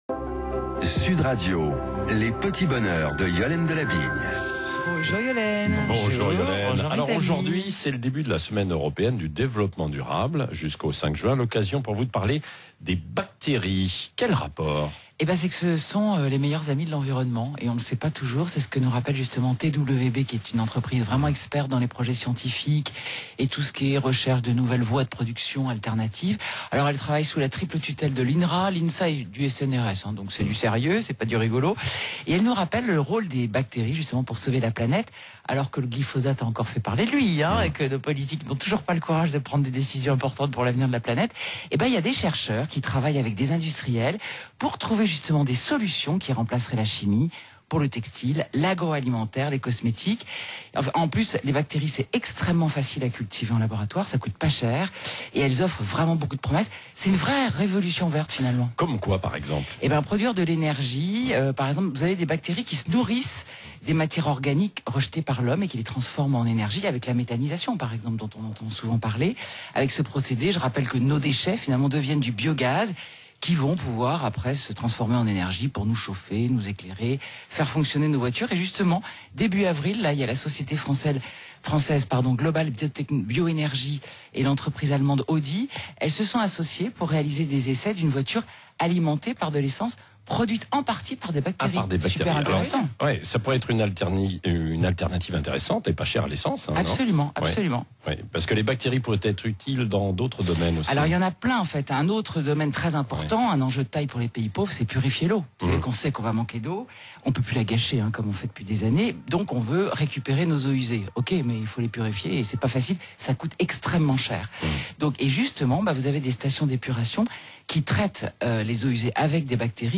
Les bactéries au secours de l’environnement – Chronique Sud Radio Le grand matin